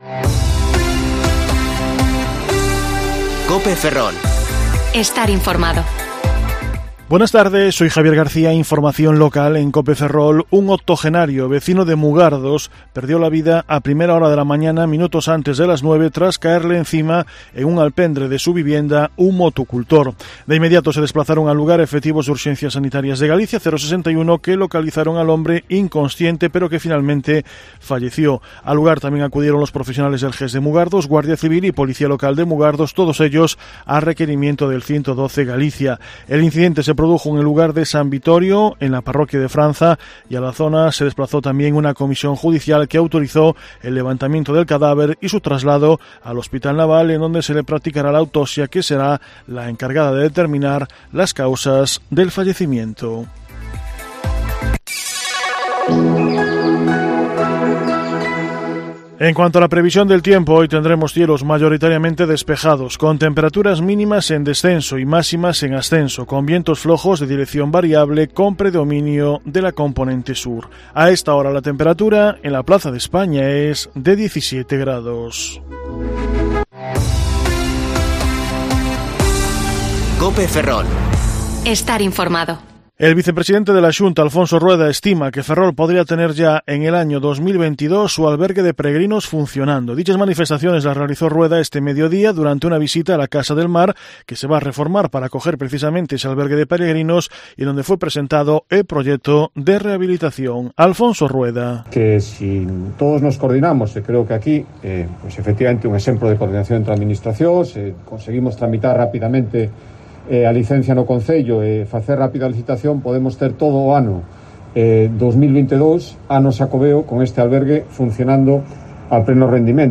Informativo Mediodía COPE Ferrol 3/3//2021 (De 14,20 a 14,30 horas)